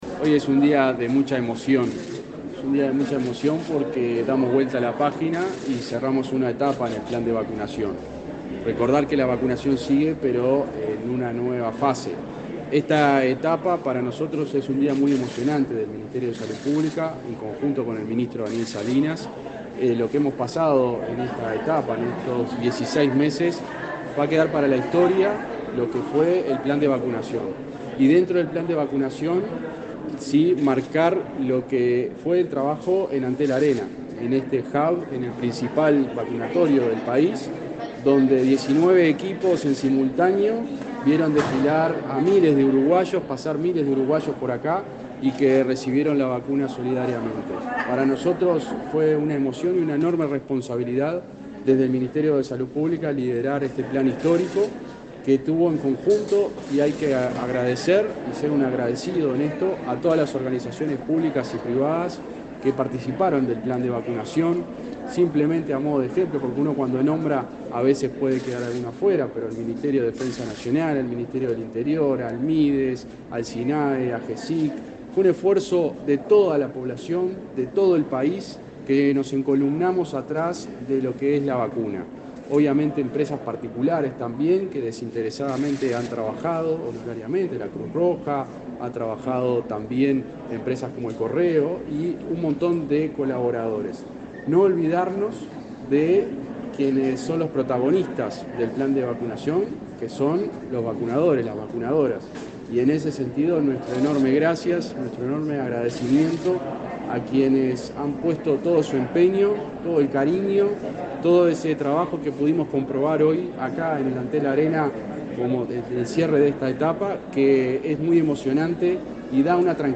Palabras de autoridades en recorrida por el vacunatorio del Antel Arena
Palabras de autoridades en recorrida por el vacunatorio del Antel Arena 24/06/2022 Compartir Facebook X Copiar enlace WhatsApp LinkedIn El ministro interino de Salud Pública, José Luis Satdjian, y el presidente de Antel, Gabriel Gurméndez, dialogaron con la prensa luego de visitar al personal que hasta hoy trabajó en el vacunatorio de ese complejo.